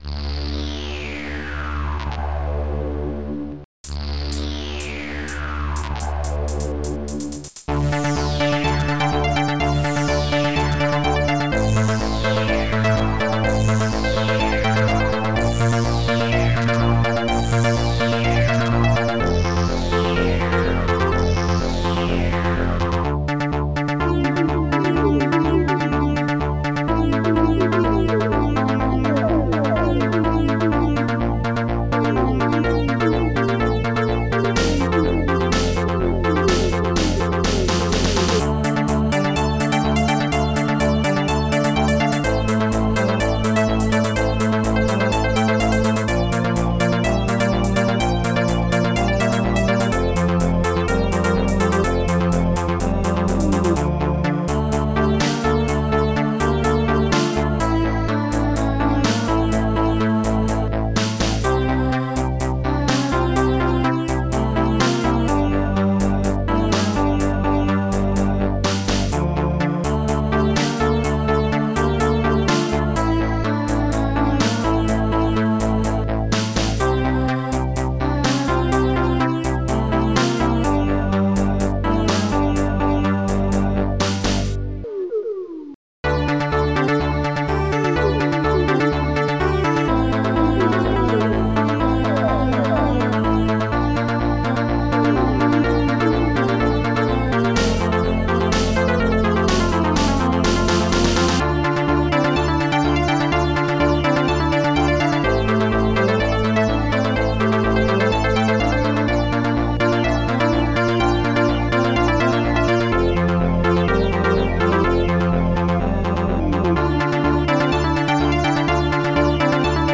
Protracker Module
Type Protracker and family
Instruments st-02:Reflex st-04:fadesynth st-04:Bassdrum5 st-01:HiHat2 st-06:human st-05:heartbounce st-04:longsweep